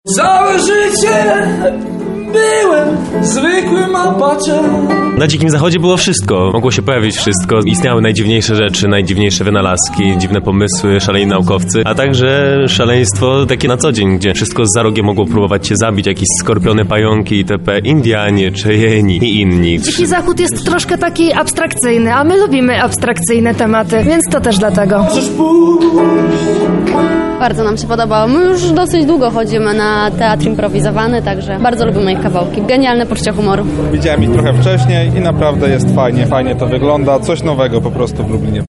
Bezczelni na dzikim zachodzie, czyli impro-serial w odcinkach - Radio Centrum